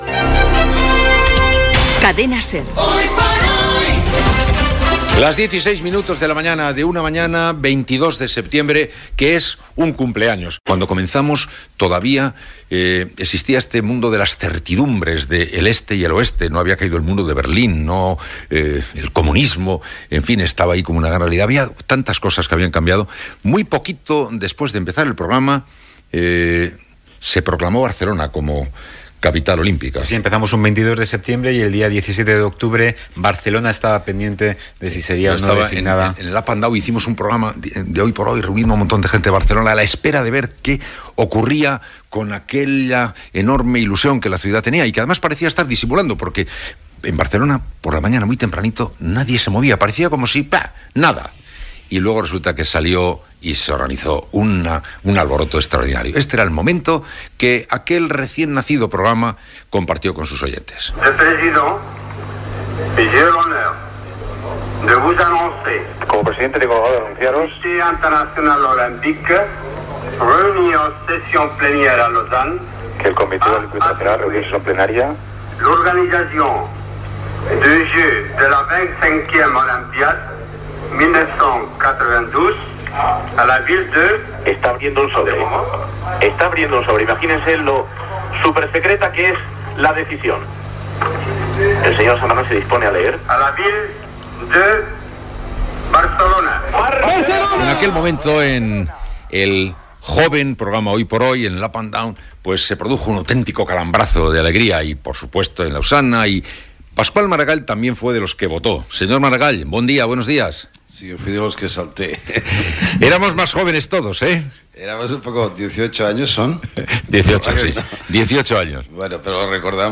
Celebració del 18è aniversari del programa "Hoy por hoy", record al dia de la concessió dels JJOO de 1992 a la ciutat de Barcelona, entrevista al president de la Generalitat, Pasqual Maragall.
Info-entreteniment